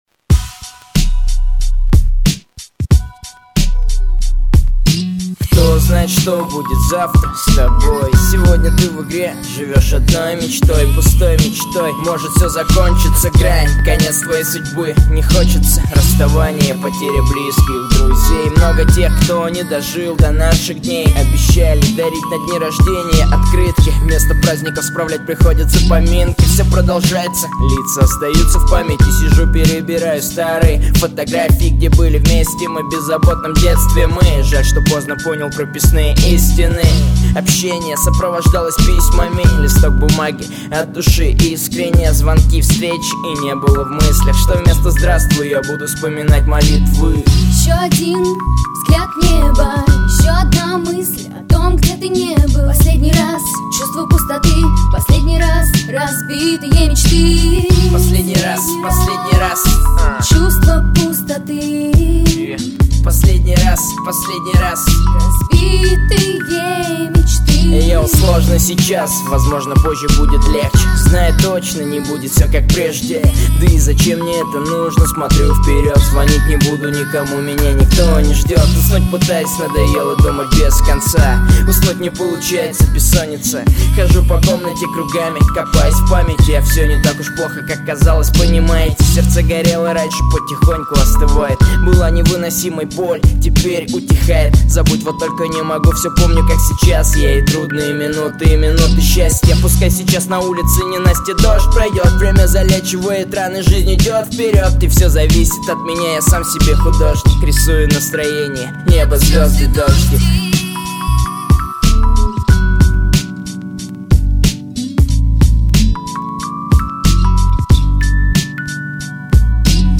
2007 Рэп